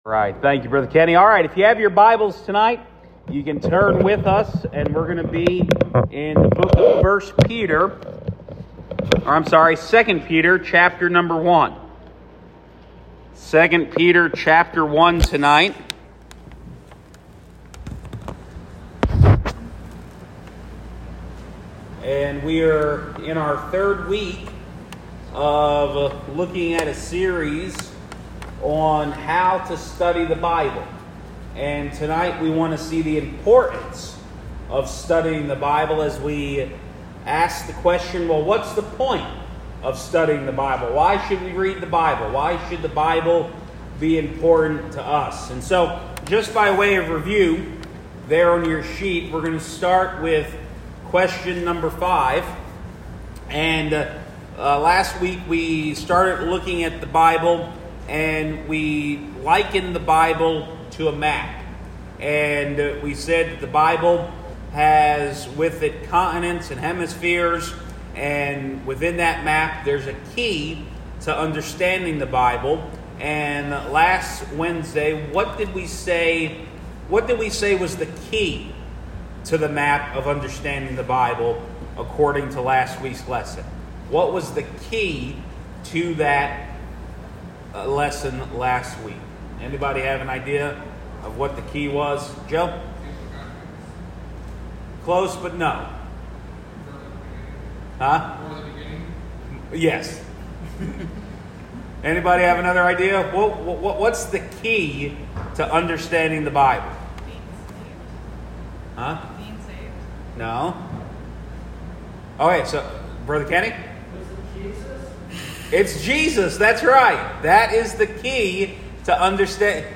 Sermons | First Baptist Church of Sayre, PA
Wednesday Evening Bible Study